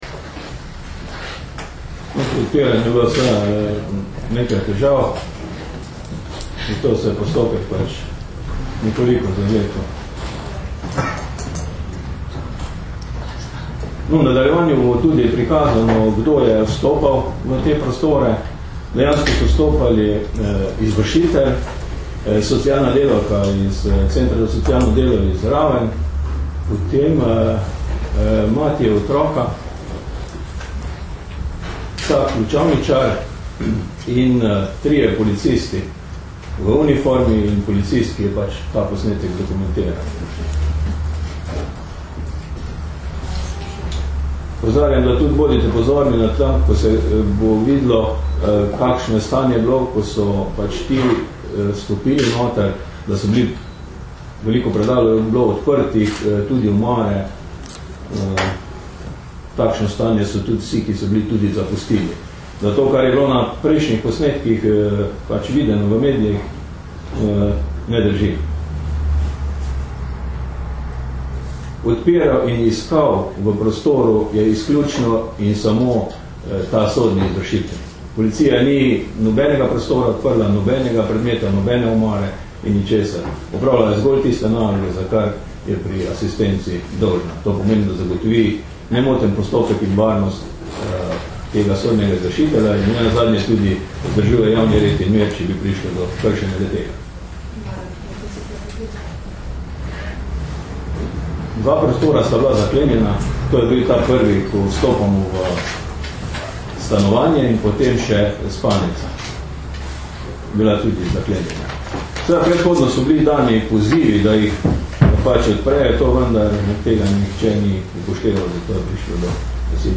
Da bi bila javnost objektivneje seznanjena z nedavnimi dogodki na Koroškem, smo danes, 9. marca, na novinarski konferenci pojasnili pristojnosti policije in okoliščine teh dogodkov.